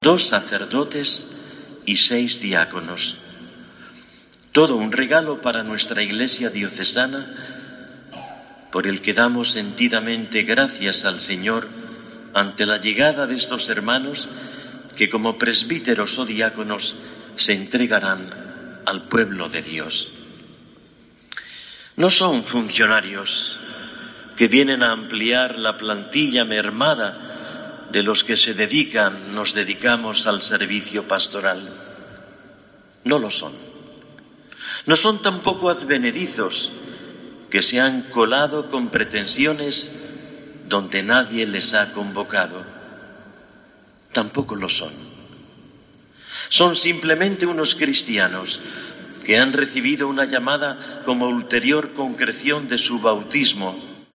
La ceremonia ha tenido lugar en la Catedral de Oviedo y, en su homilía, Mons. Jesús Sanz Montes ha dado gracias a Dios por lo que califica de “todo un regalo para nuestra Iglesia diocesana”.